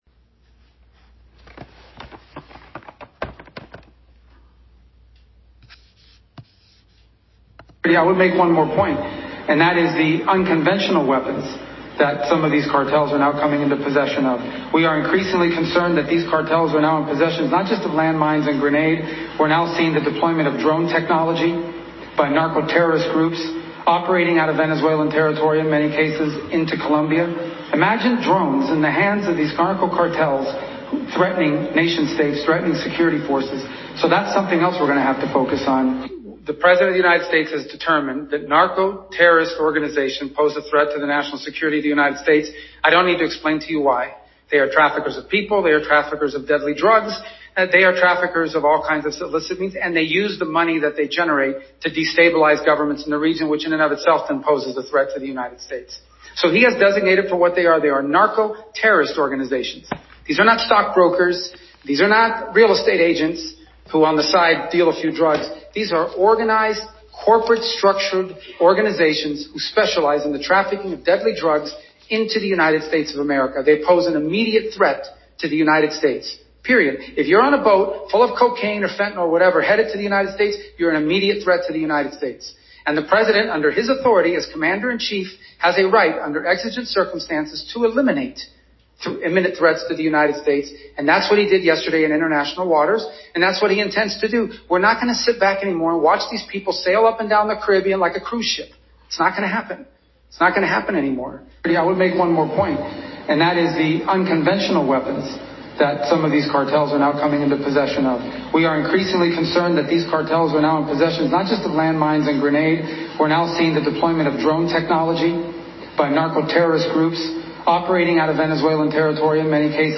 “The United States has long, for many, many years, established intelligence that allow us to interdict and stop drug boats. We did that. And it doesn’t work,” Rubio said at a press conference in Mexico City.